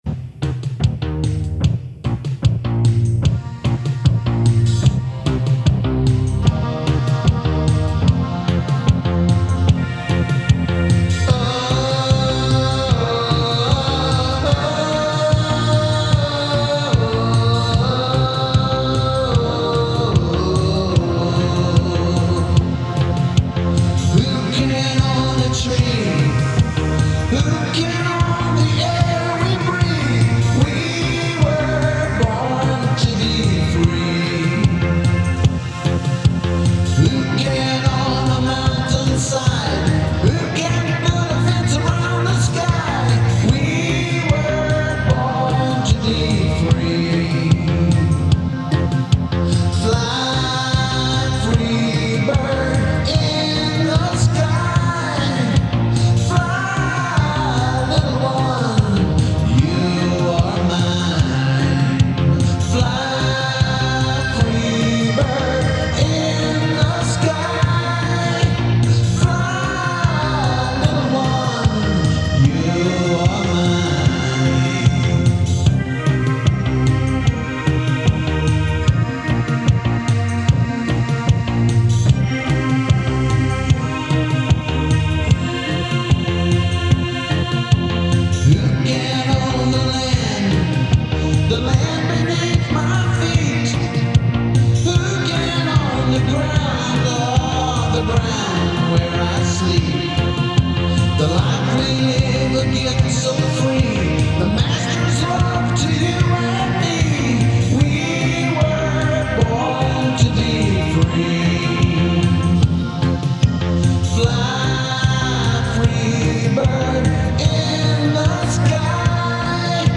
NATIVE AMERICAN CONTEMPORY